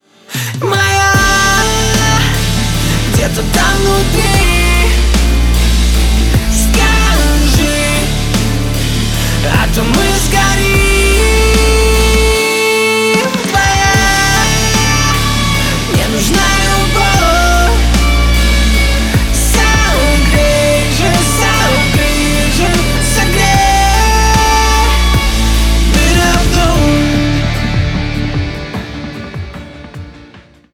• Качество: 320 kbps, Stereo
Поп Музыка
громкие